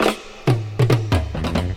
MoTown Fill.wav